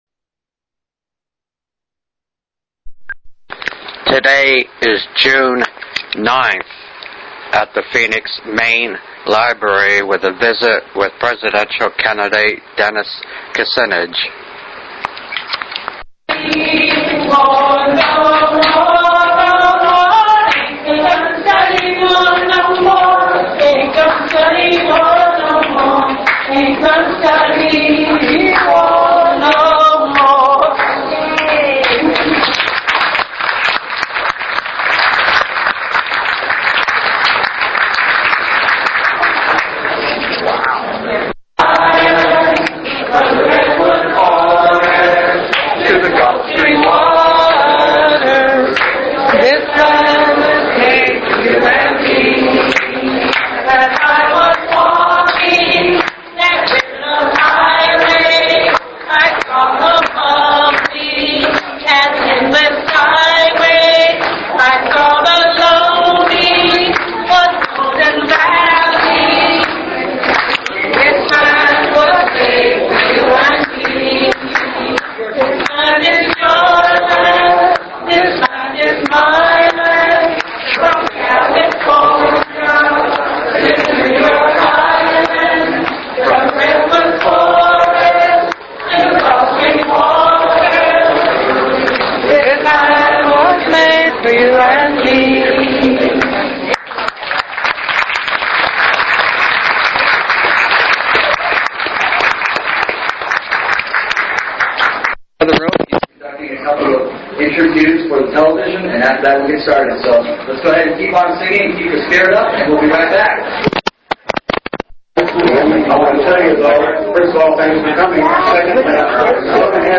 65.57 Minute Audio of Presidential candidate Dennis Kucinich visit to Phoenix Az meeting over 250 activists at Phoenix main library.